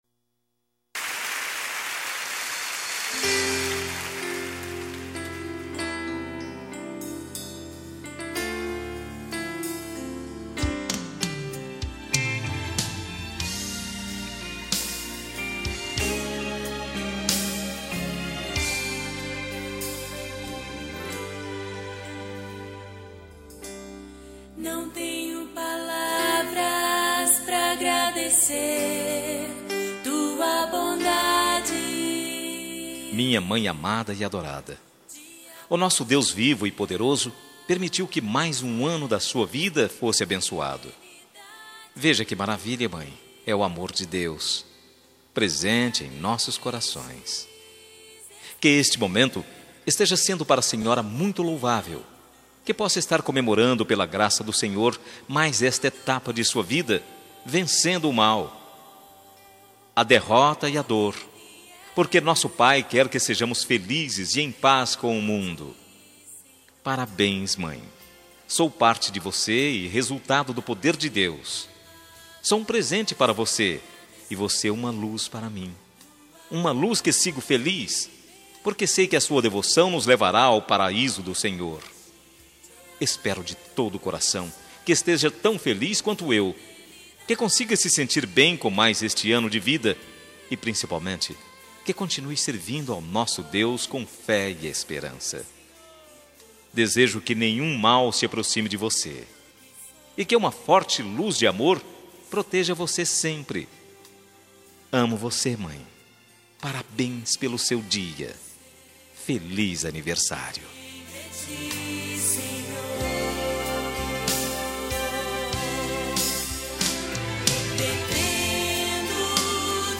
Aniversário de Mãe Gospel – Voz Masculina – Cód: 6069